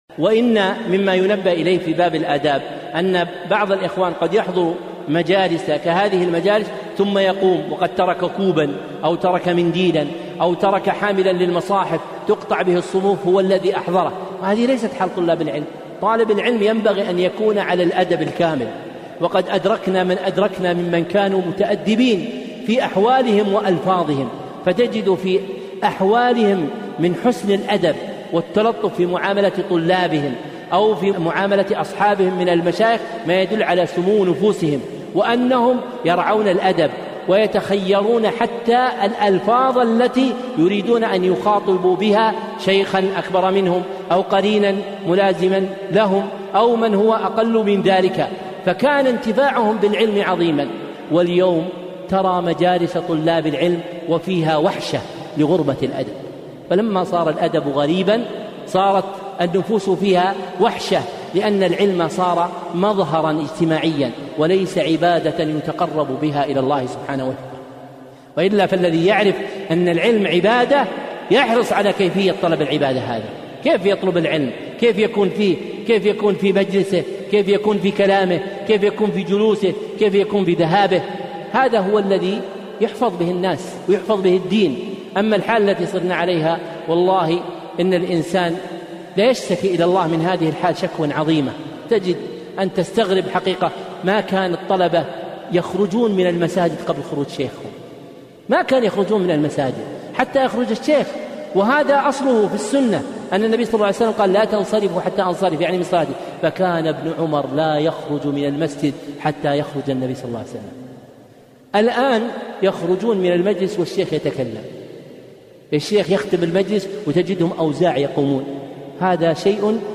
يبكي على حال طلاب العلم اليوم